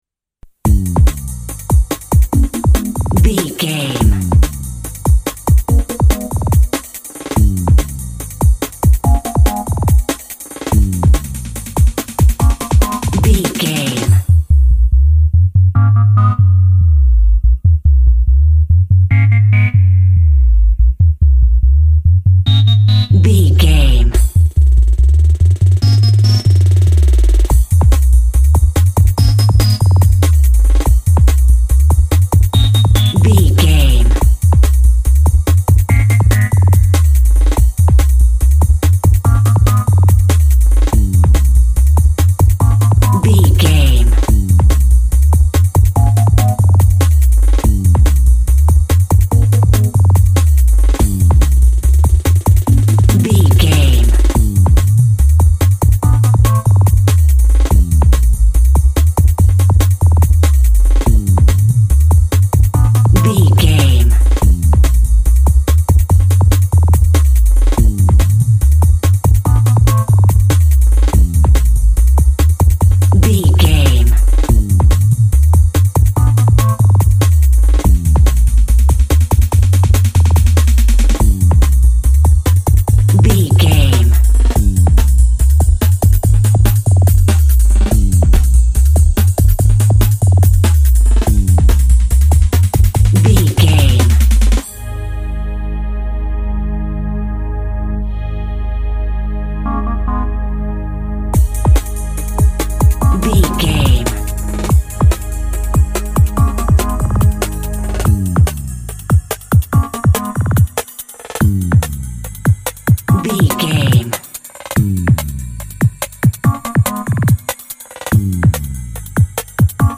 Epic / Action
Fast paced
Aeolian/Minor
groovy
synthesiser
drum machine
laid back
nu jazz
downtempo
synth lead
synth bass